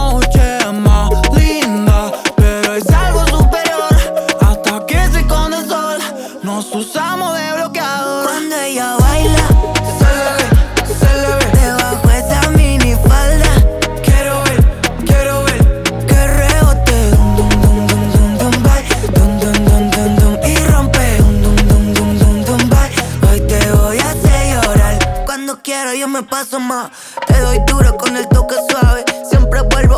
Жанр: Альтернатива / Латиноамериканская музыка